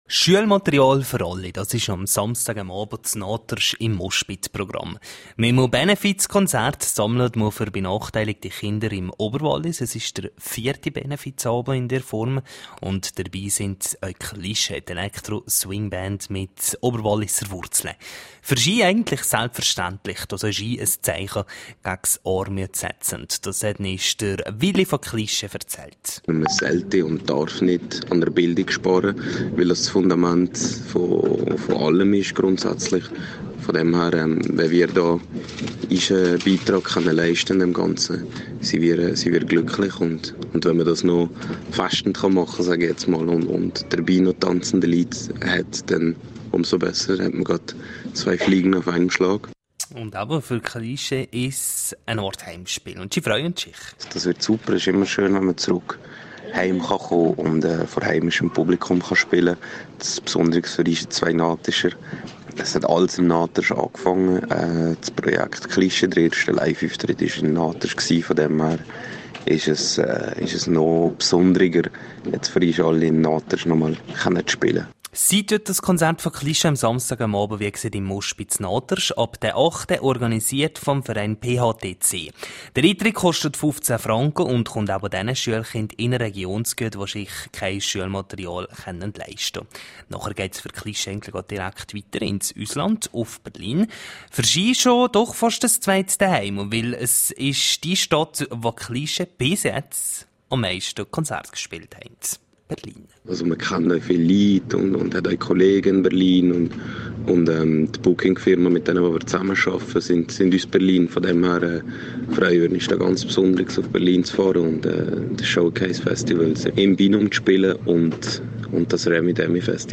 17:00 Uhr Nachrichten (4.05MB)